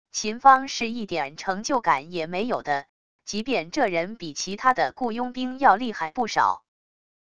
秦方是一点成就感也没有的……即便这人比其他的雇佣兵要厉害不少wav音频生成系统WAV Audio Player